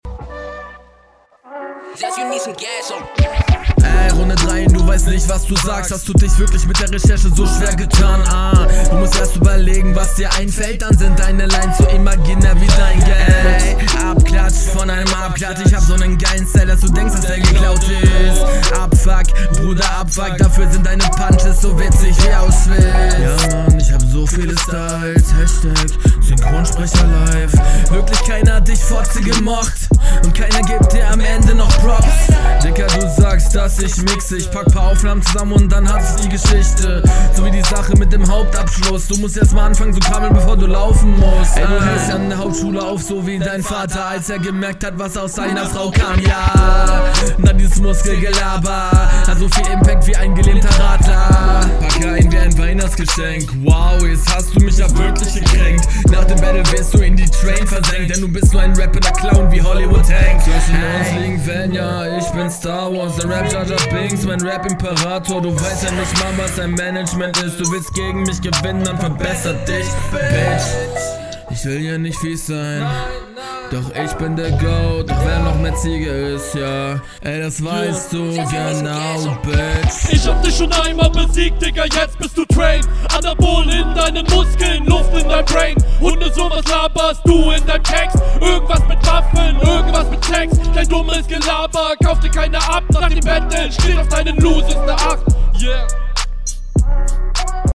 Flowst weitestgehend sauber über den Beat, vielleicht etwas zu monoton.
Damn, klingst sehr fett in der Runde.
Schöne Abmische. Hier hast du einen schönen Flow.